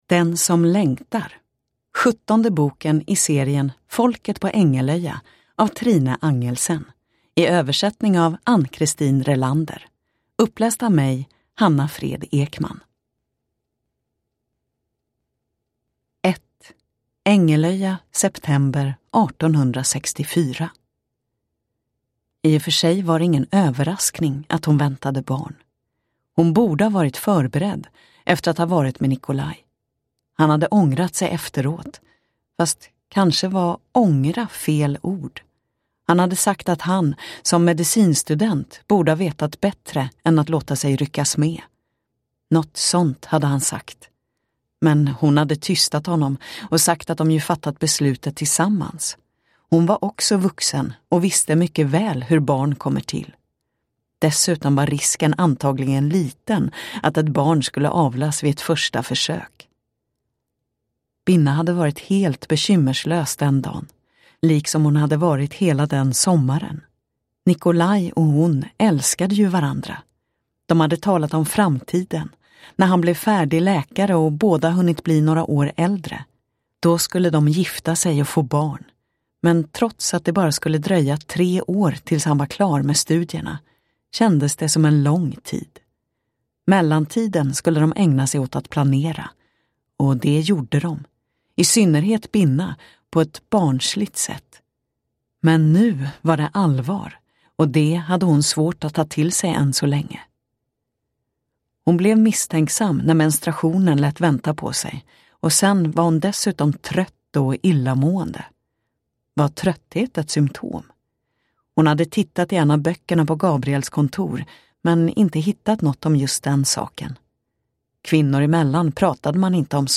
Den som längtar – Ljudbok – Laddas ner